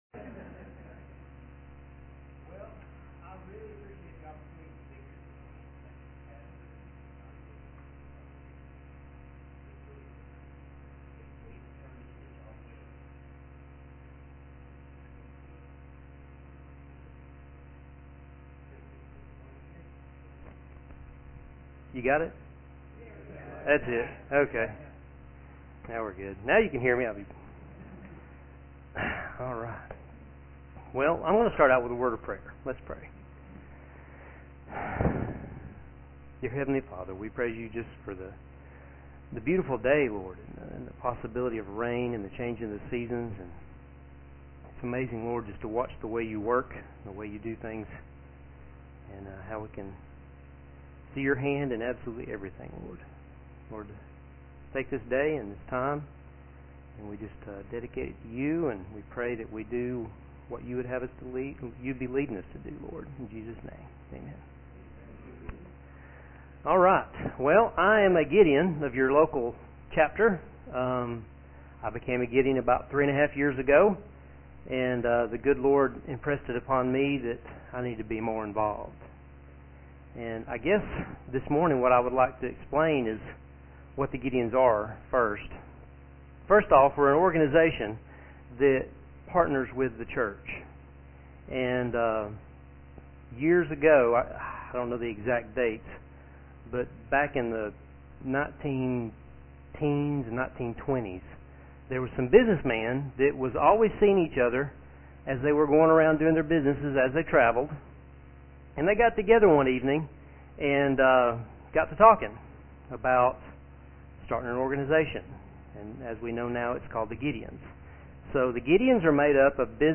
Special Guest Speaker